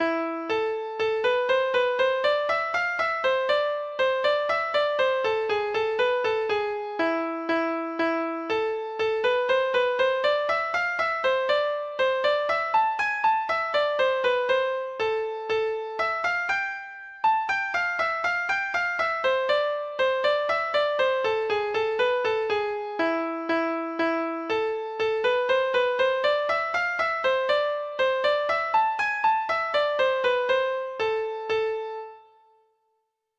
Folk Songs from 'Digital Tradition' Letter G Gilderoy
Treble Clef Instrument  (View more Intermediate Treble Clef Instrument Music)
Traditional (View more Traditional Treble Clef Instrument Music)